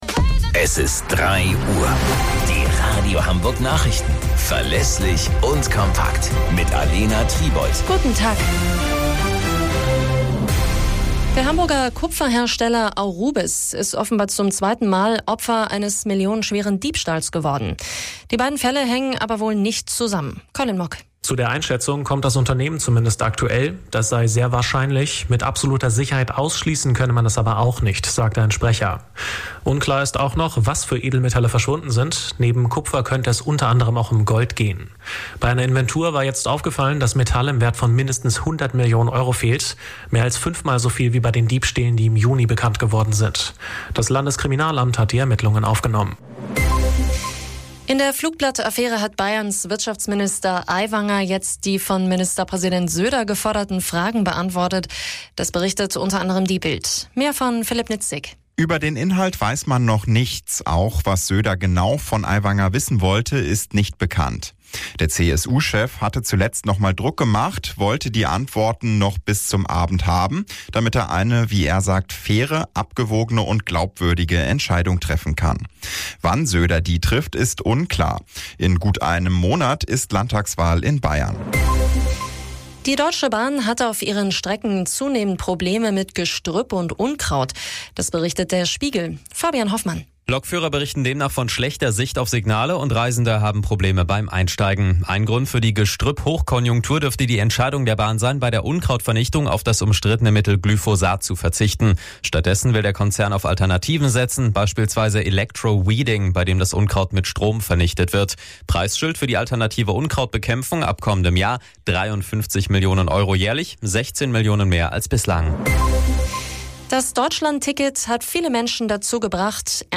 Radio Hamburg Nachrichten vom 02.09.2023 um 08 Uhr - 02.09.2023